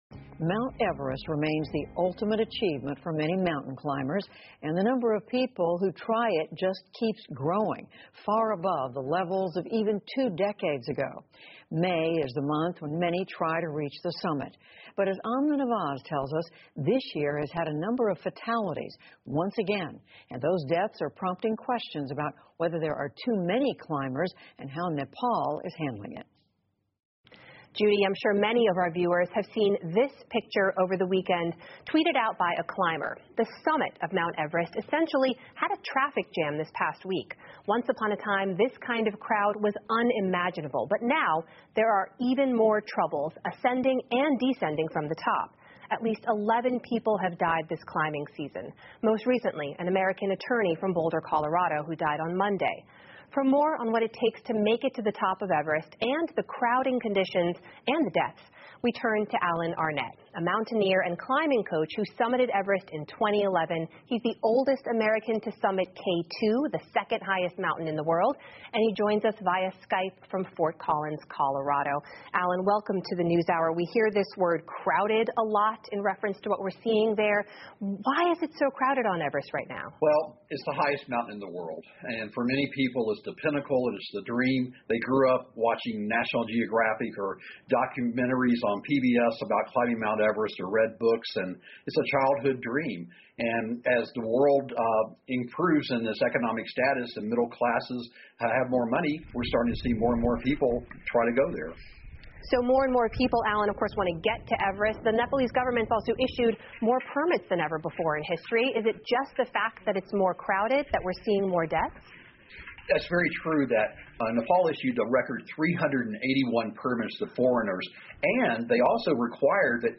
在线英语听力室PBS高端访谈:极为拥挤的珠穆朗玛峰的听力文件下载,本节目提供PBS高端访谈环境系列相关资料,内容包括访谈音频和文本字幕。